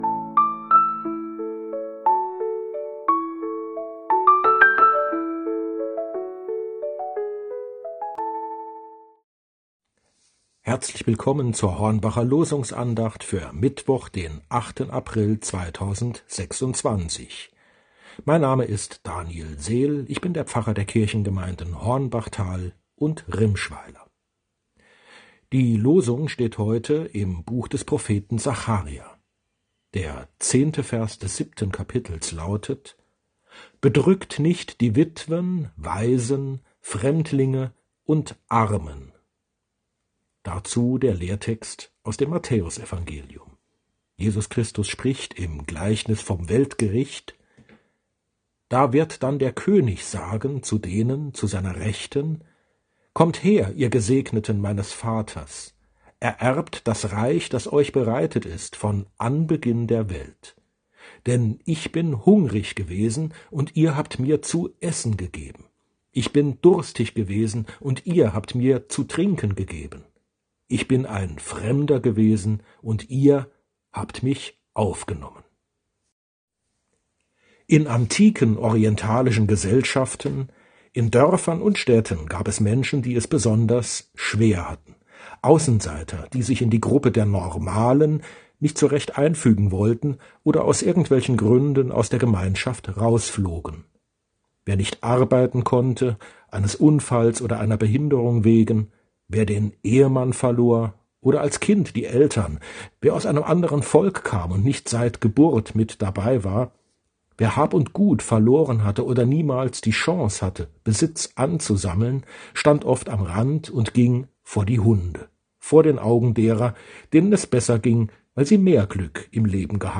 Losungsandacht für Mittwoch, 08.04.2026